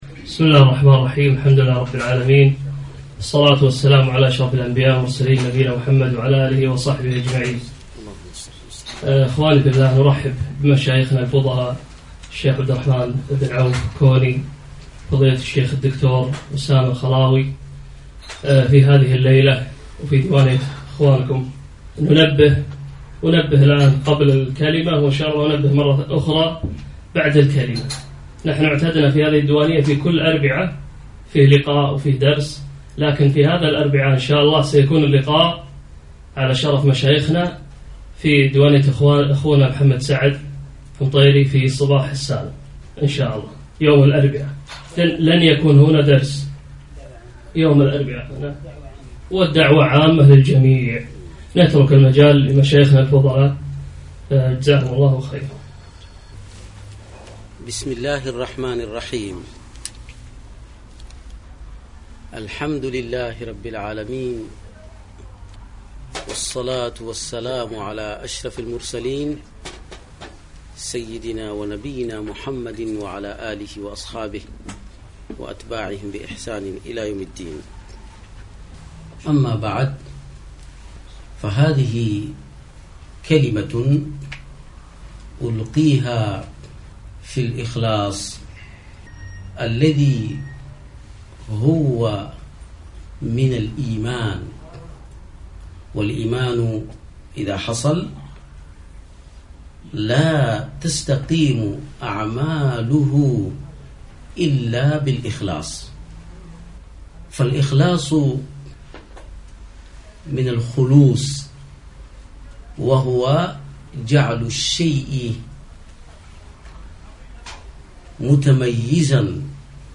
كلمة عامة